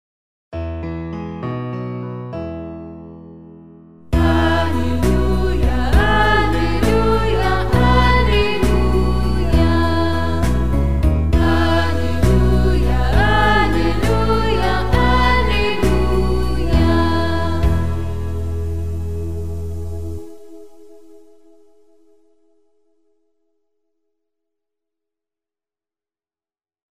Chants divers
alleluia_3_chant.mp3